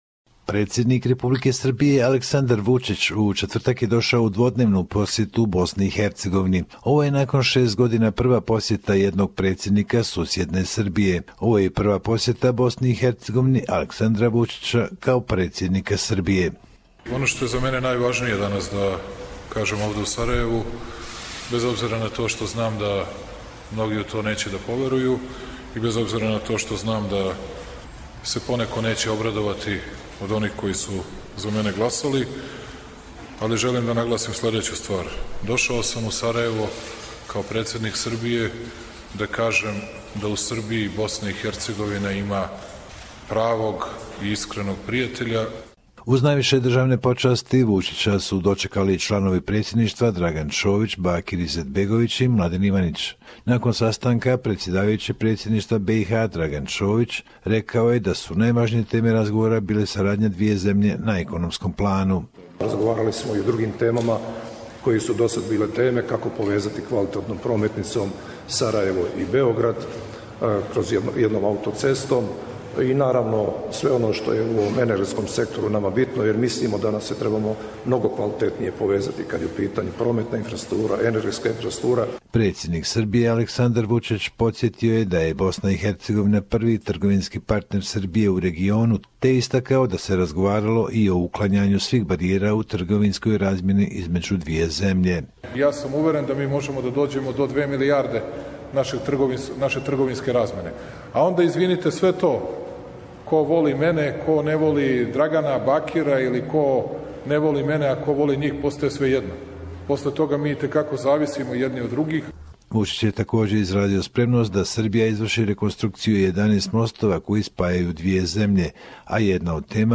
Report from Bosnia and Herzegovina